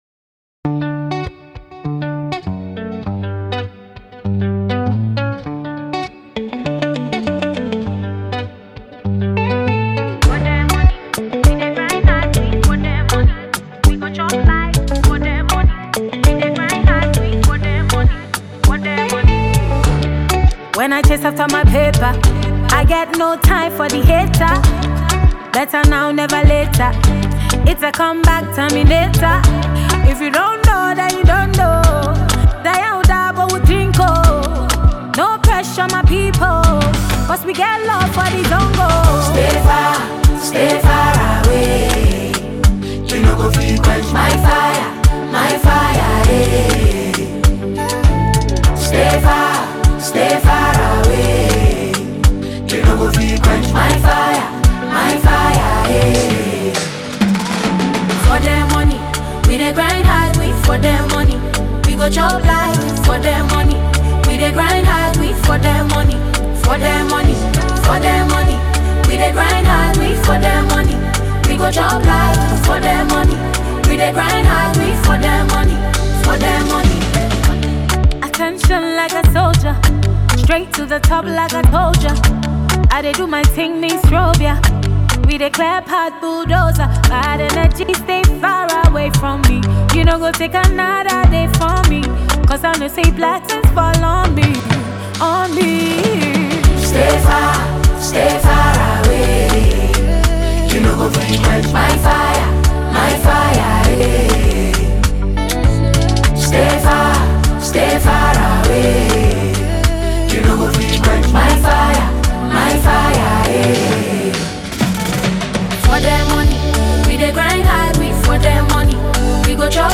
Ghana Music
beautifully crafted mid-tempo record
powerful yet velvety vocals